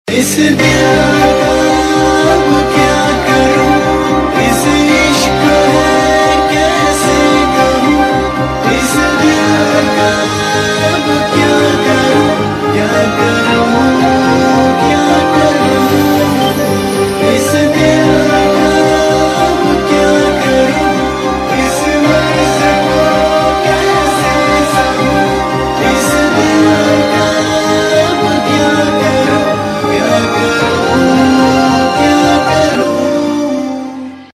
TV Serial Tone